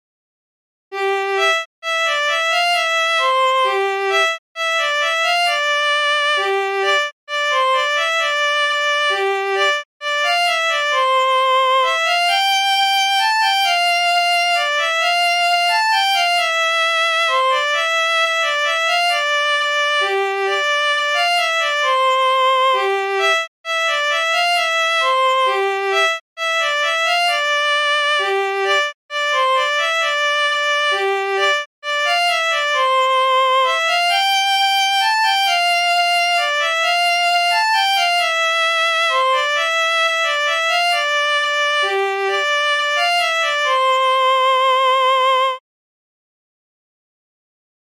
Masurca gascona - Masurca